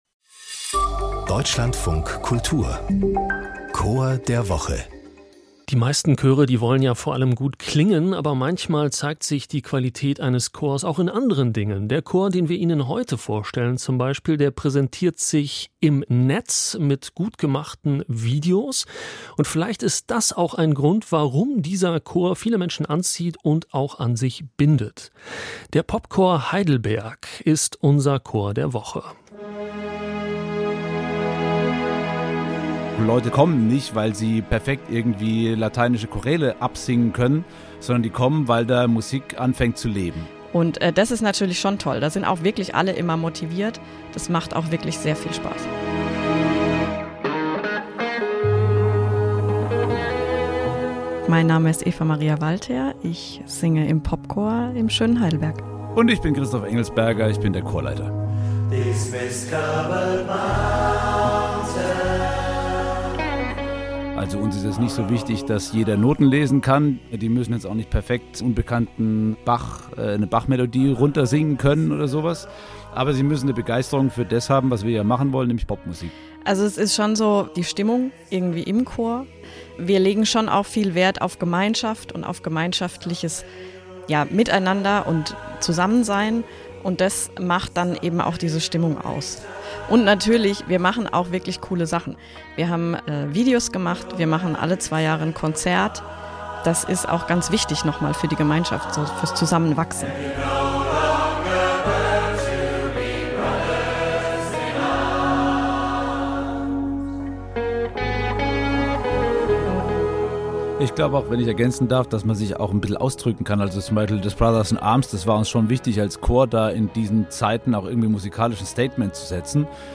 Popchor Heidelberg